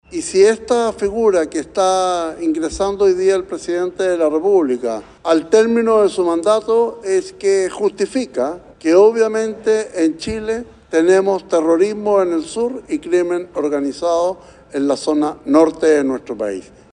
El senador de la UDI, José Miguel Durana, dijo que la medida, en la última etapa de Gobierno, revela la presencia de terrorismo en el norte y sur del país.